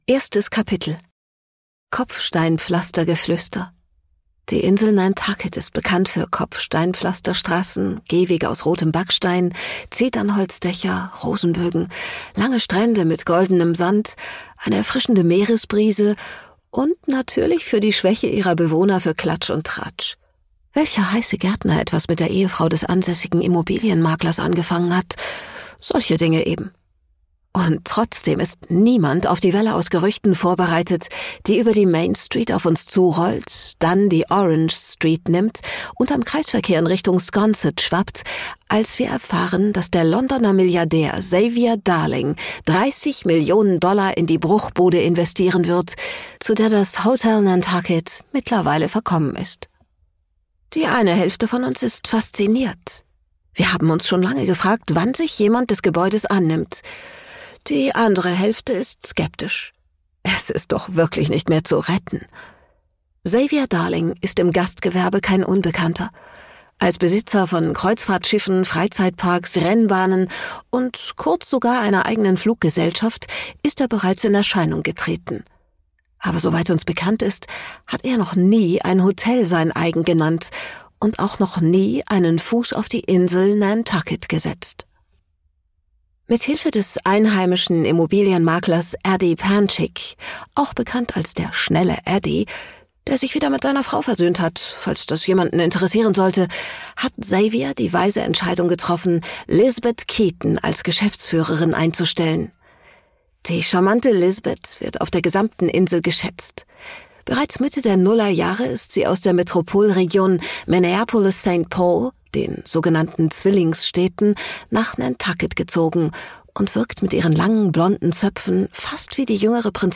Produktionsart: ungekürzt